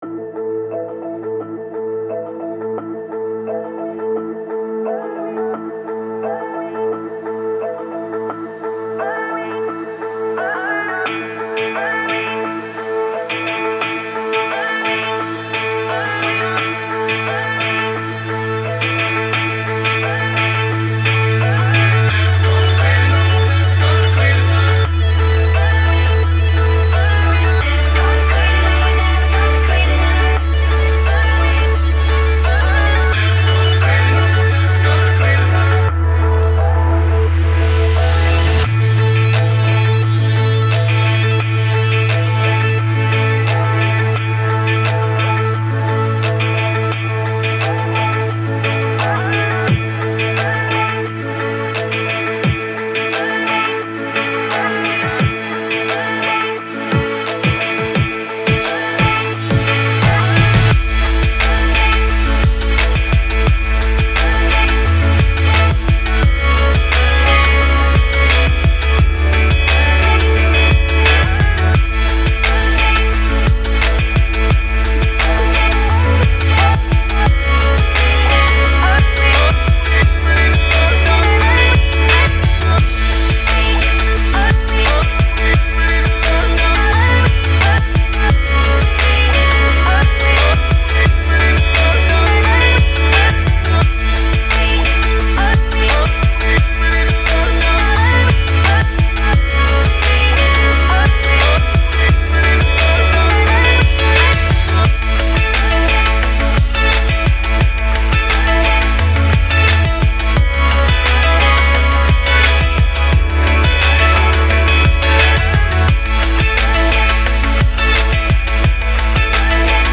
” and had it remixed to give it a “dream pop” sound.